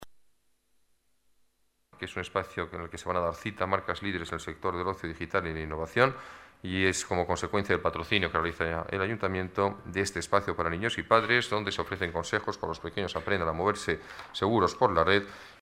Nueva ventana:Declaraciones alcalde, Alberto Ruiz-Gallardón: e-LIFE 2008